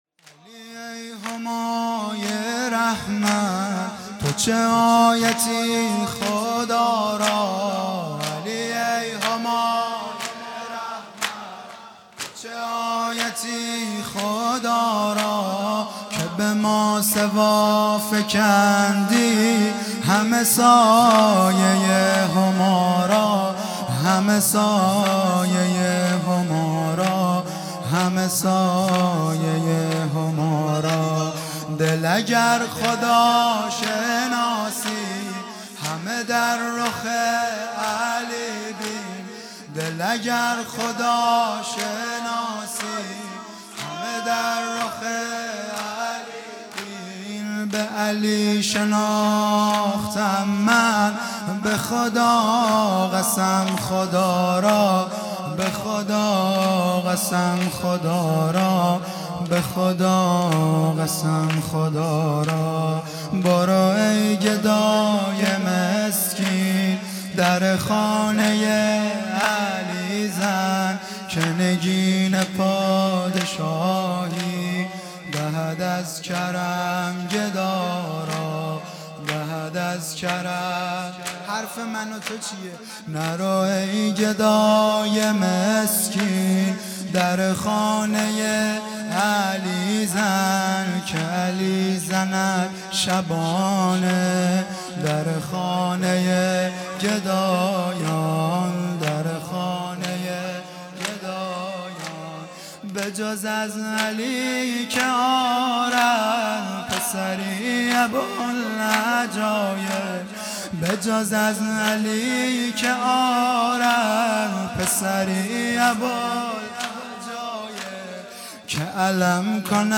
جلسه هفتگی